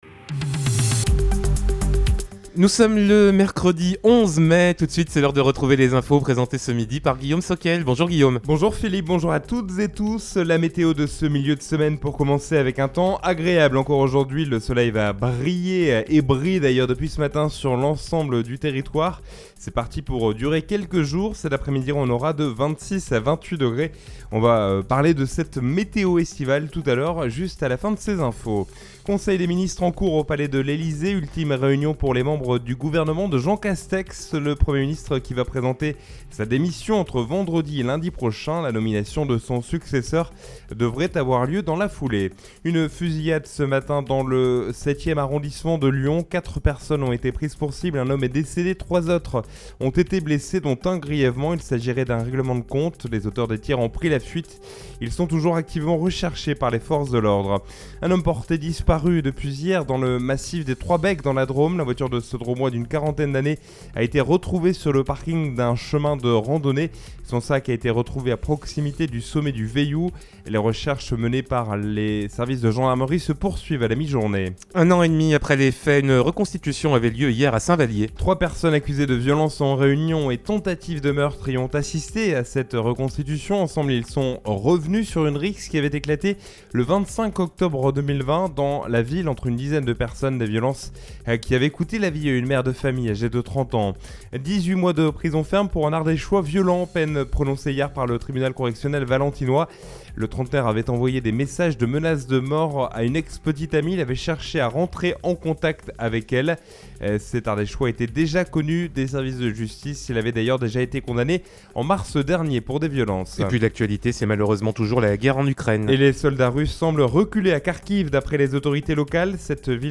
Tous les jours, Chérie FM Vallée du Rhône fait un point sur l’actualité locale, nationale et internationale.